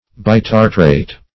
Search Result for " bitartrate" : Wordnet 3.0 NOUN (1) 1. an acid tartrate ; a hydrogen tartrate ; The Collaborative International Dictionary of English v.0.48: Bitartrate \Bi*tar"trate\, n. (Chem.)